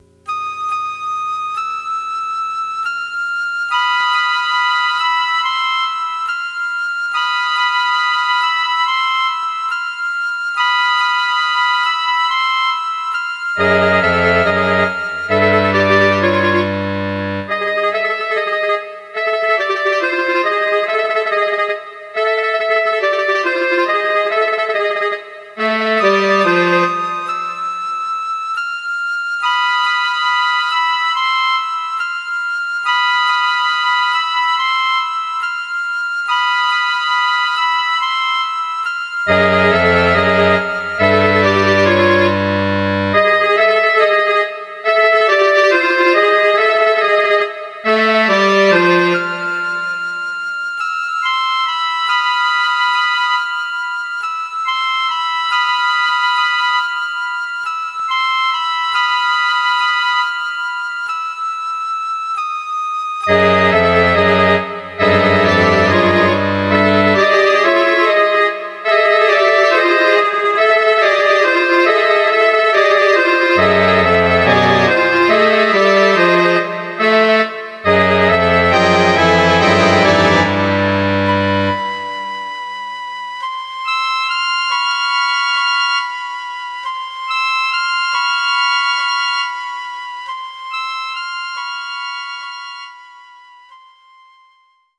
Orchestral music
electronic compostiton